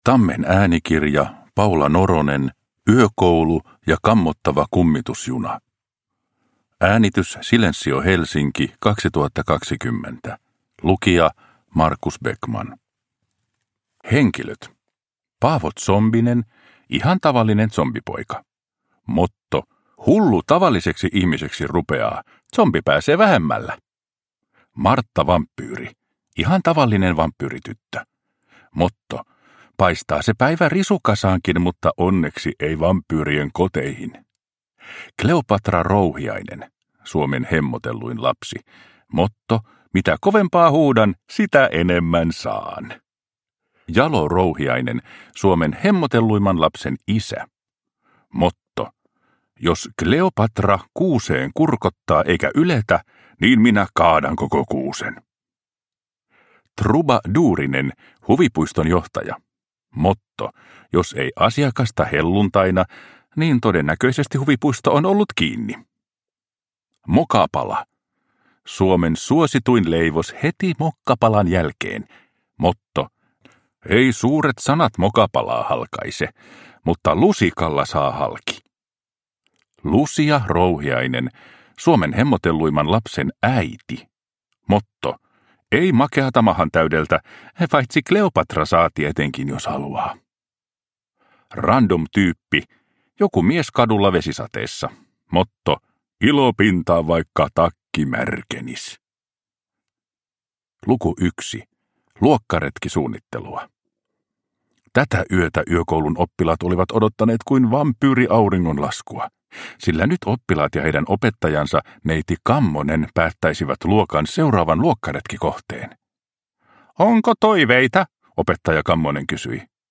Yökoulu ja kammottava kummitusjuna – Ljudbok